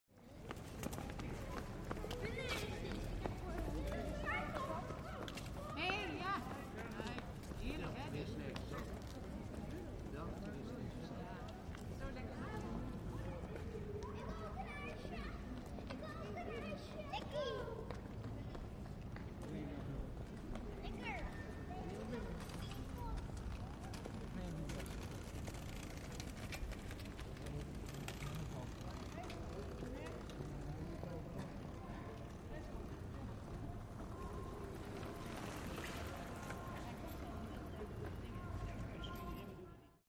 دانلود آهنگ خیابان شهری از افکت صوتی طبیعت و محیط
جلوه های صوتی
دانلود صدای خیابان شهری از ساعد نیوز با لینک مستقیم و کیفیت بالا